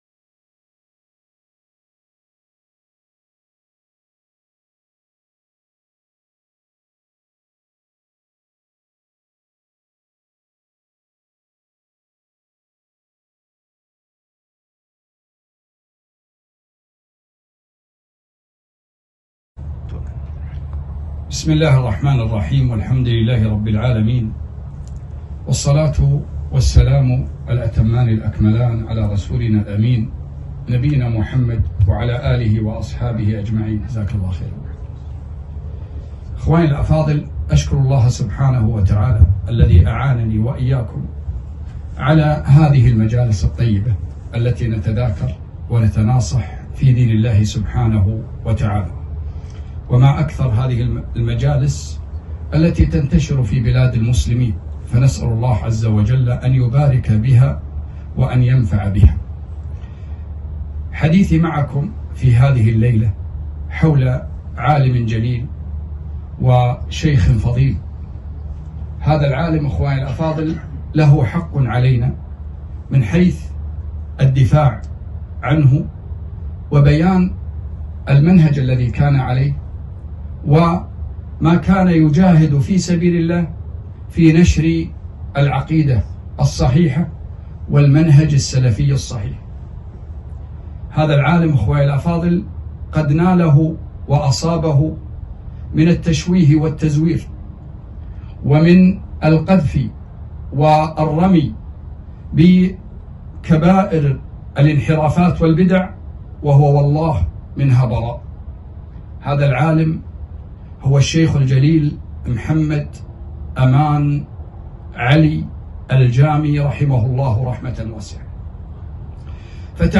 كلمة - يا حبذا الجامي